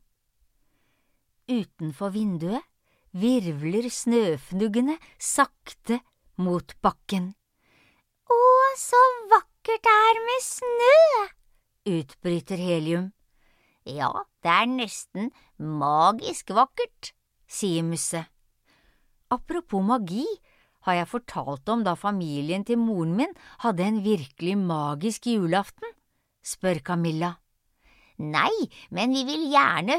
Et magisk juleeventyr (lydbok) av Camilla Brinck